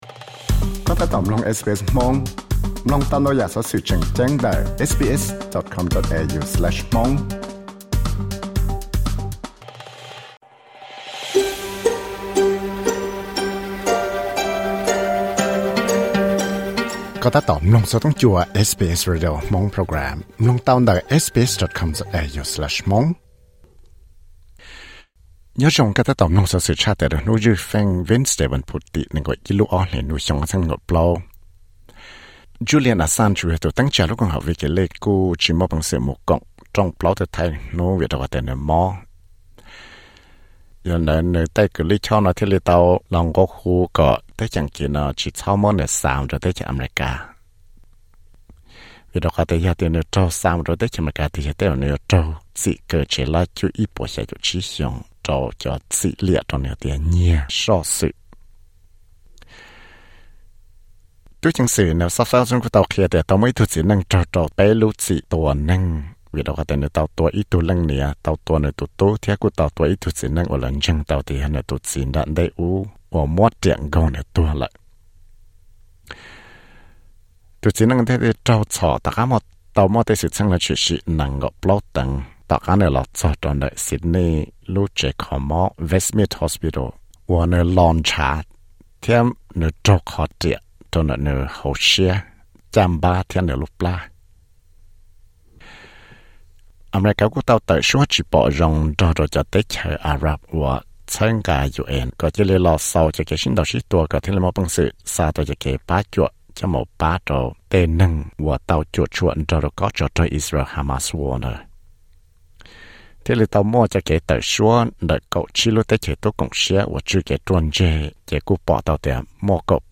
Xov xwm luv tshaj tawm
Wednesday newsflash